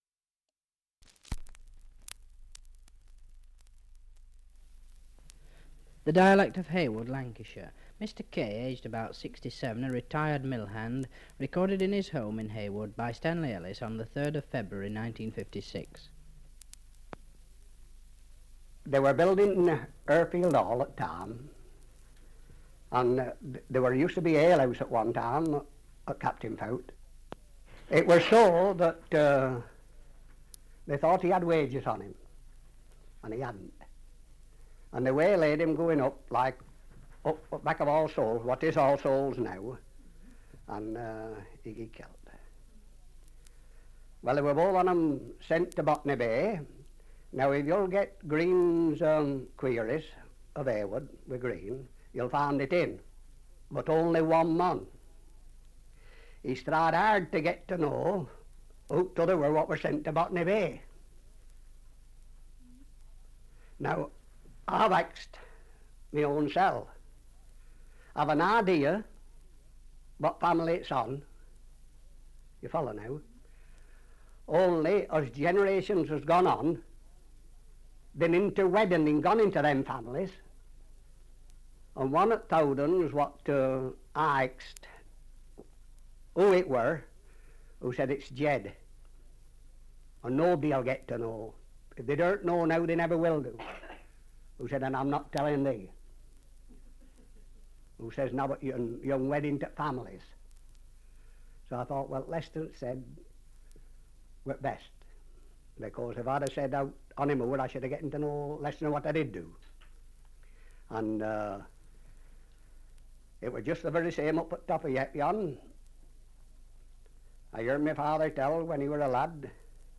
Dialect recording in Heywood, Lancashire
78 r.p.m., cellulose nitrate on aluminium